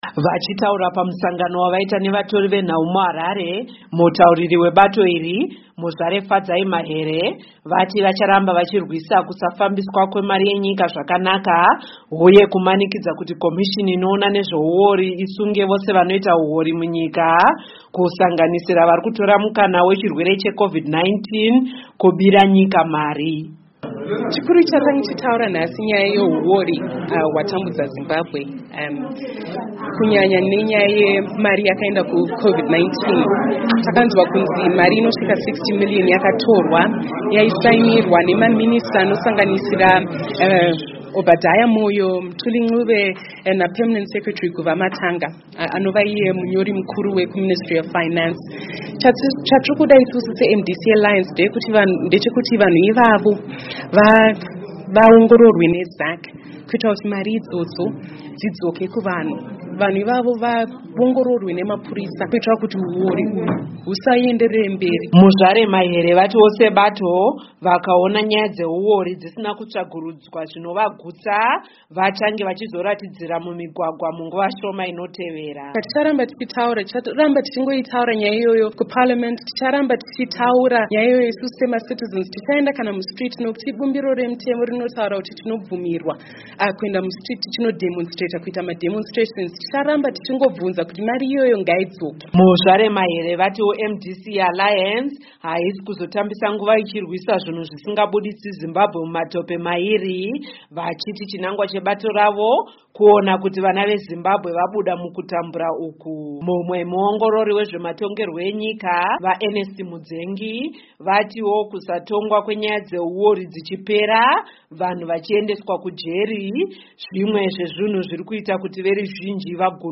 Advocate Fadzayi Mahere Mutauriri weMDC Alliance Vachitaura Pamusoro Pezvaitika
Vachitaura pamusangano wavaita nevatori venhau muHarare, mutauriri webato iri, Muzvare Fadzayi Mahere, vati vacharamba vachirwisa kusafambiswa kwemari yenyika zvakanaka huye kumanikidza kuti komishini inoona nezvehuori isunge vese vanoita huori munyika kusanganisira vari kutora mukana wechirwere cheCOVID-19 kubira nyika mari.